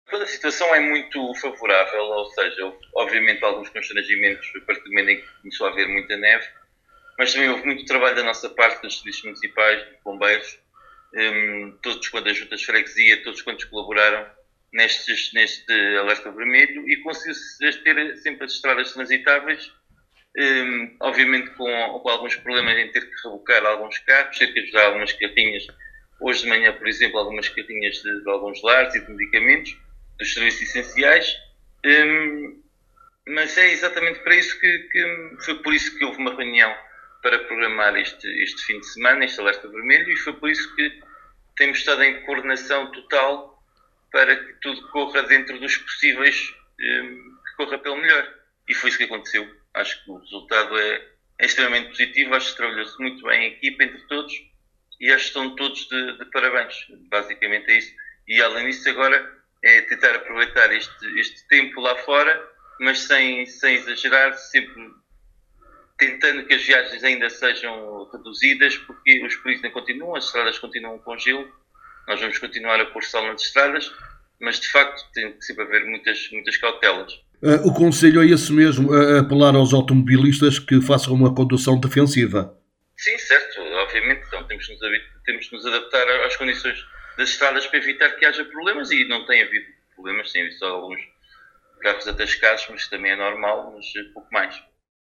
Paulo Marques, Presidente do Município e responsável da Proteção Civil do concelho de Vila Nova de Paiva, em declarações à Alive Fm fez o ponto da situação das ocorrências desta sexta e sábado (23 e 24 janeiro), afirmando que apesar de terem existido alguns constrangimentos devido à forte queda de neve, os serviços municipais e juntas de freguesia em articulação com os Bombeiros estiveram em bom plano e deram uma boa resposta.